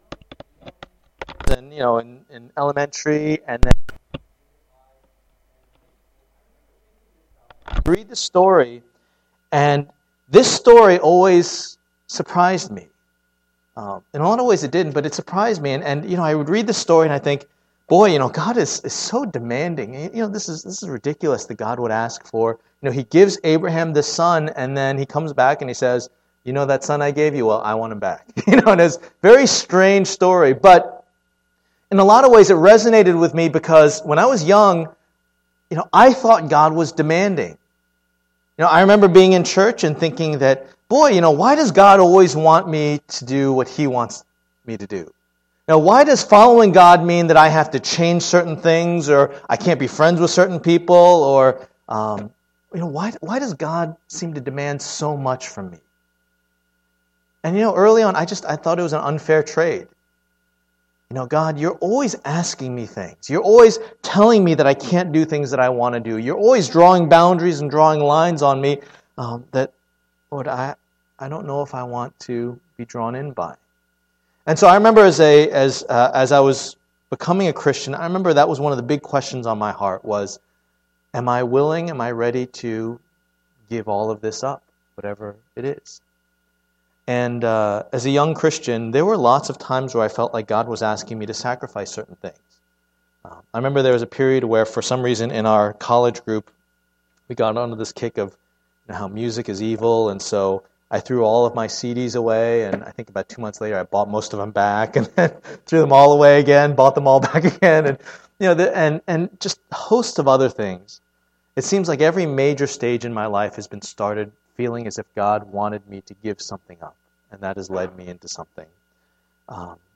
Passage: Genesis 22:1-19 Service Type: Lord's Day